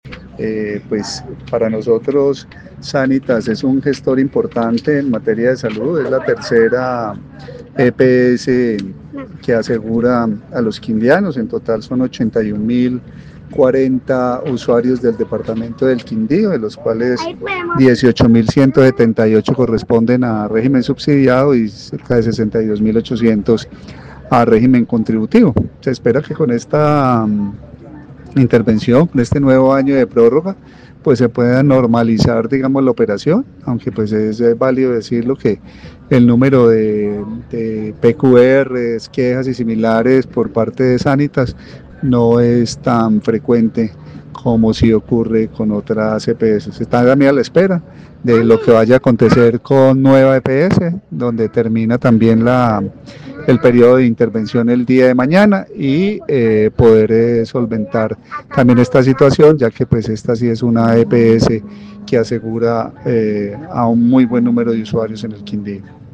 Así lo manifestó el secretario de salud del Quindío, Carlos Alberto Gómez Chacón